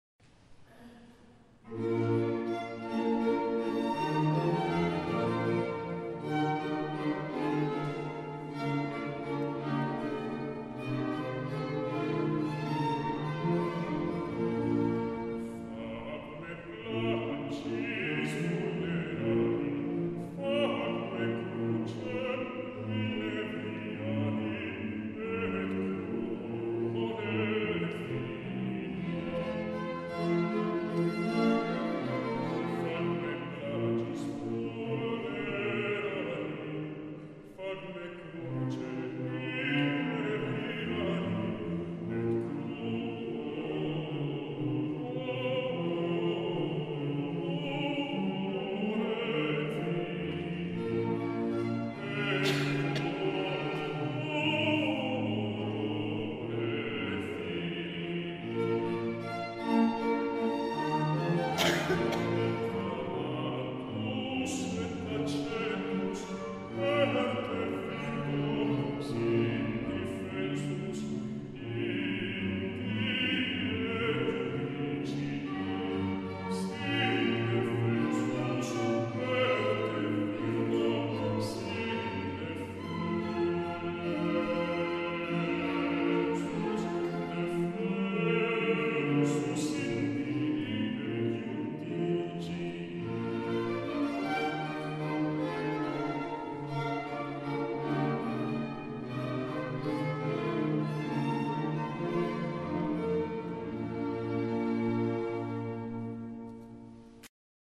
Soloist Music